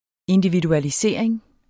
Udtale [ endividualiˈseˀɐ̯eŋ ]